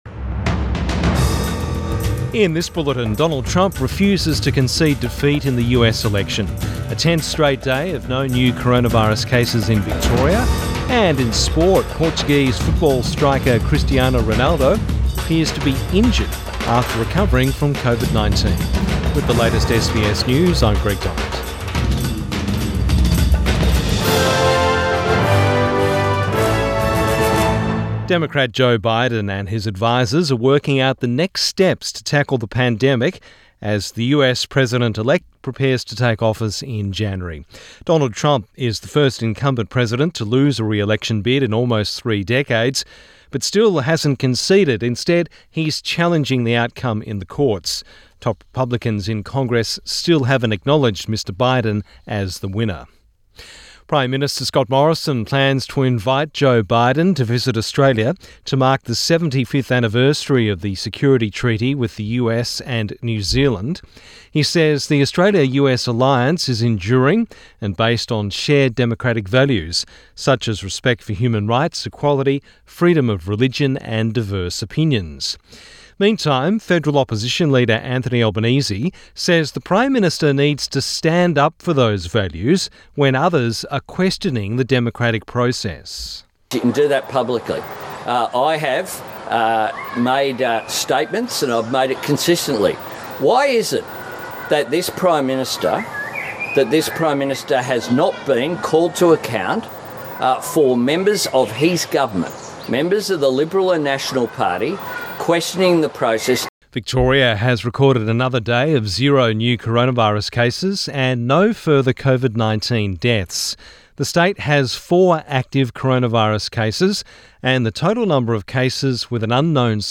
Midday bulletin 9 November 2020